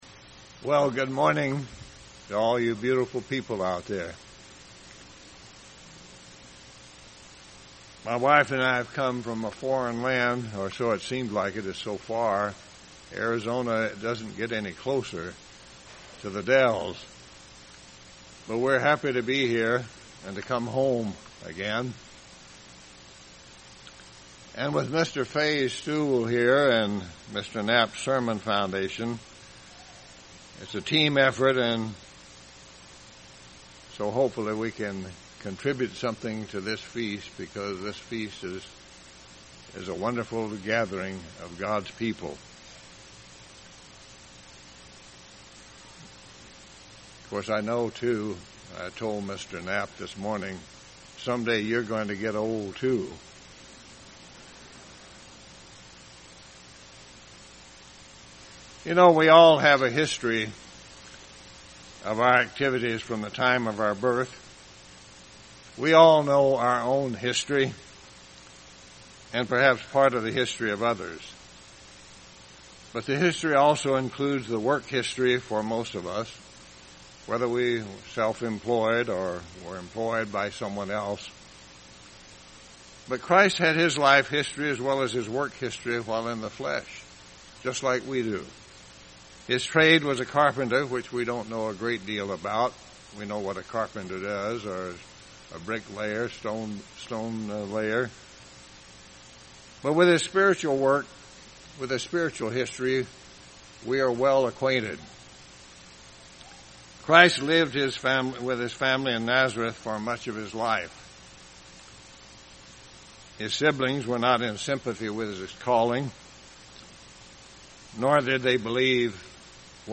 This sermon was given at the Wisconsin Dells, Wisconsin 2014 Feast site.